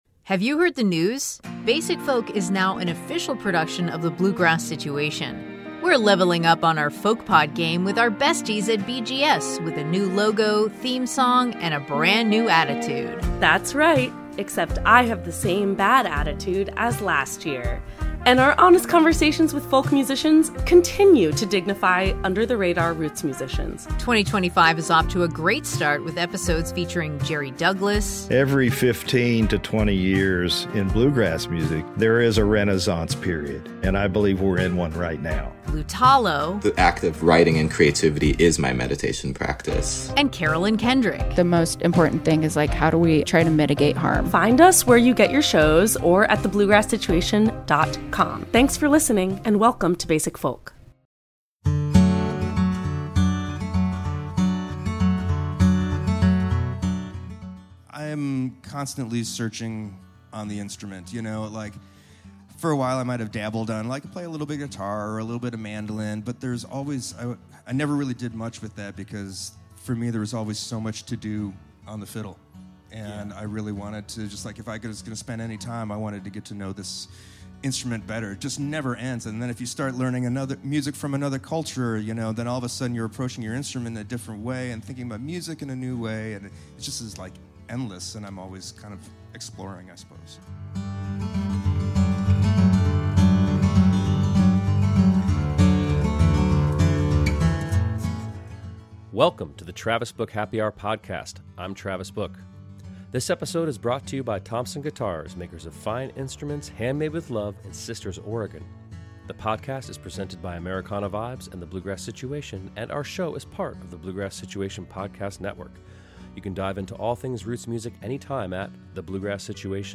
The Travis Book Happy Hour is hosted by Travis Book of the GRAMMY Award-winning band, The Infamous Stringdusters. The show’s focus is musical collaboration and conversation around matters of being. The podcast is the best of the interview and music from the live show recorded in Brevard, NC, and is brought to you by Americana Vibes and The Bluegrass Situation.